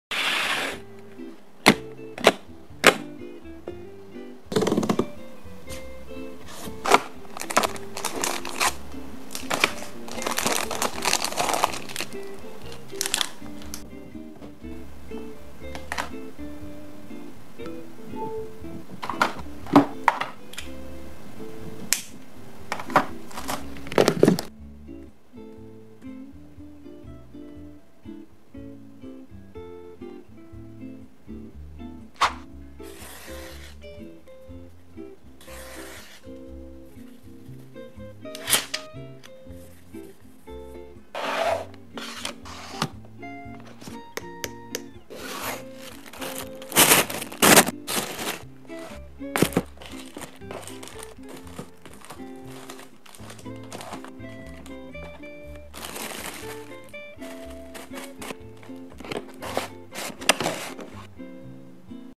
IPHONE 15 Impressive Packing Sound Effects Free Download